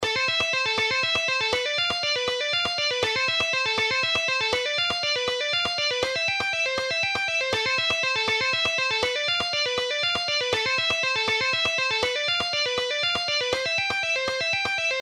Guitar Sweeping Exercises – 5
Guitar sweep picking exercises 5:
Sweep-Picking-Exercise-5-1.mp3